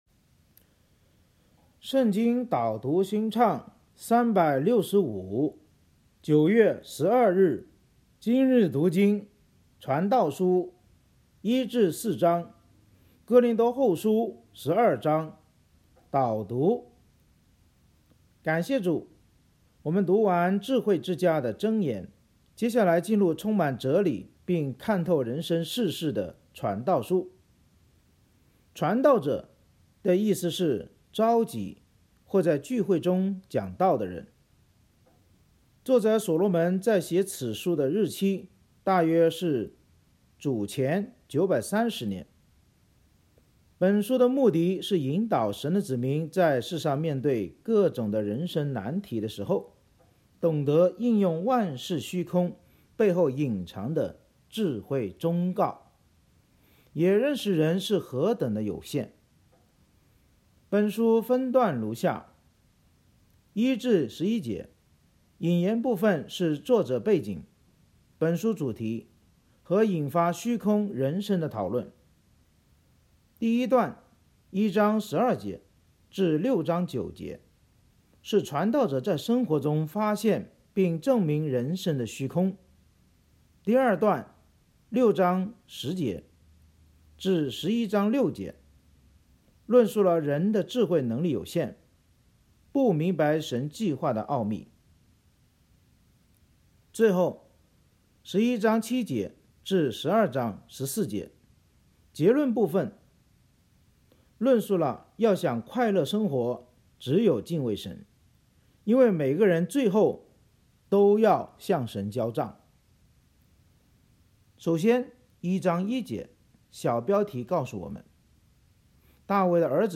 圣经导读&经文朗读 – 09月12日（音频+文字+新歌）